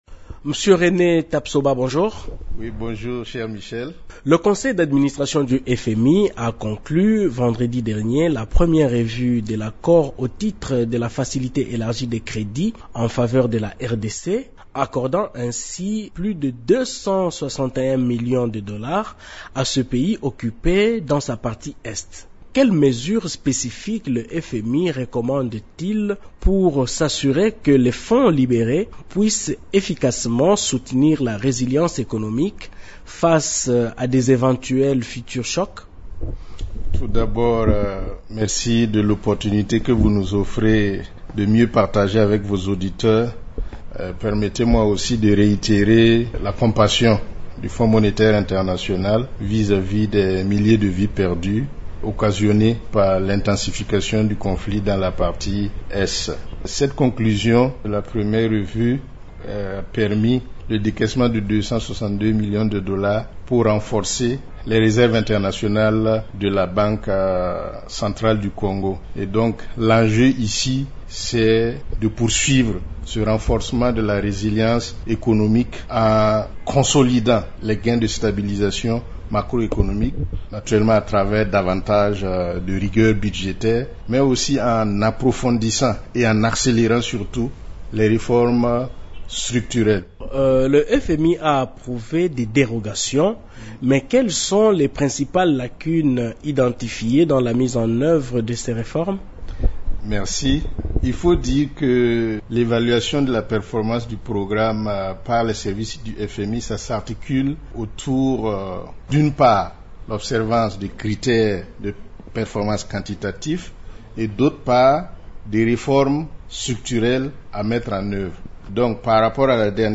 Dans un entretien exclusif accordé à Radio Okapi